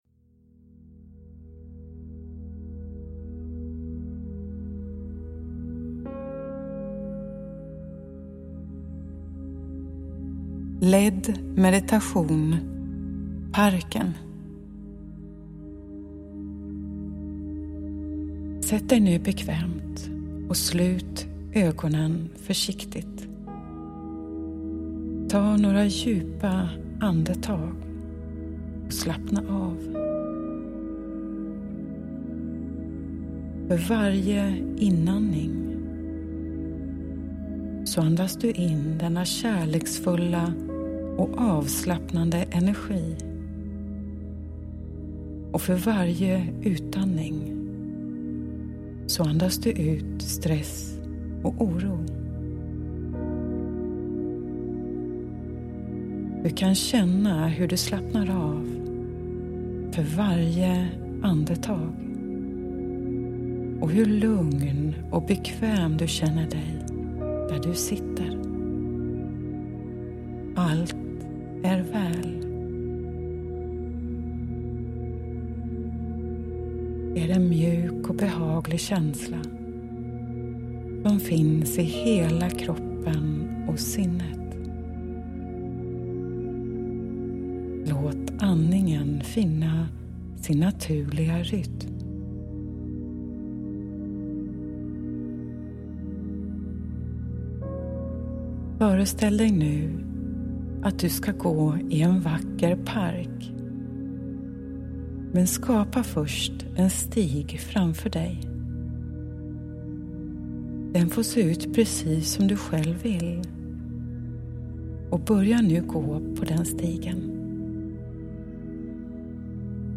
En vägledd meditation för avslappning och välmående
Spår 3 är den vägledda meditationen och Spår 4 är ett ljudspår med bara musiken så att du kan sitta tillsammans med dig själv och njuta av en egen meditation.
Njut av att gå i parken, höra fåglarna försiktigt sjunga i bakgrunden, känna en mjuk bris i ditt hår, solens strålar i ditt ansikte, den härliga doften av blommor och växter och se fjärilar dansa från blomma till blomma.